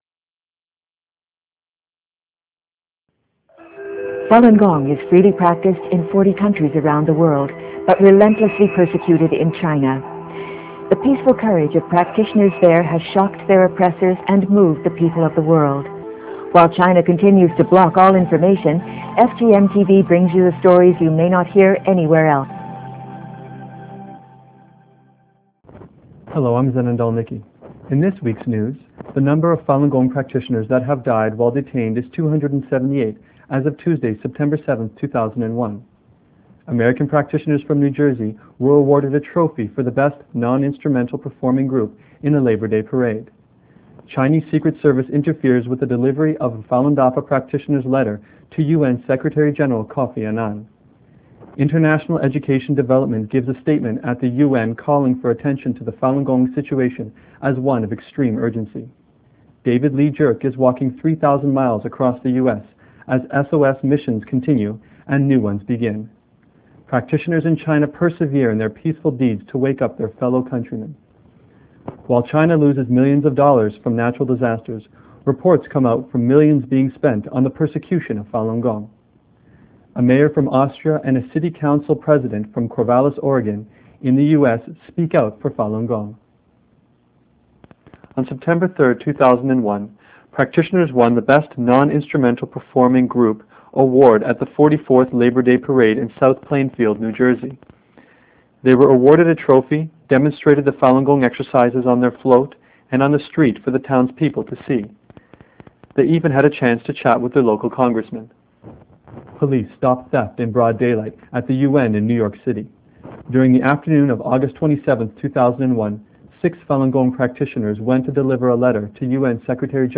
FGM News Report